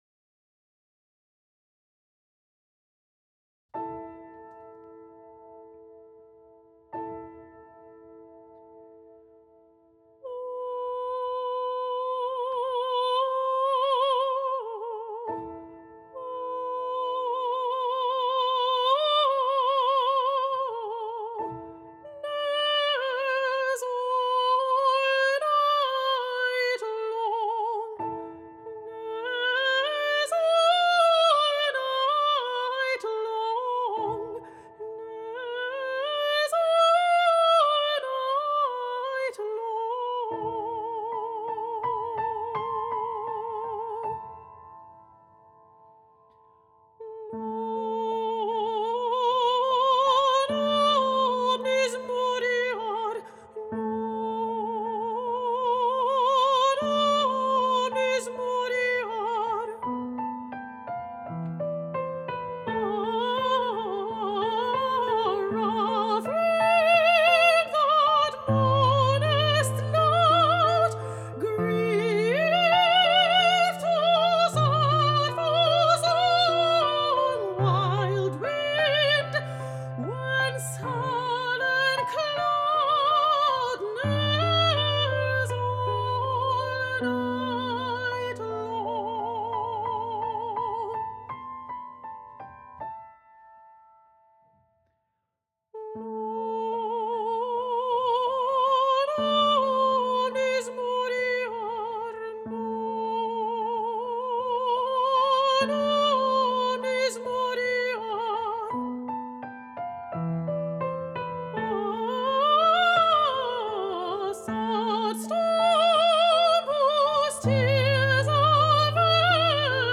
An art song
Soprano
pianist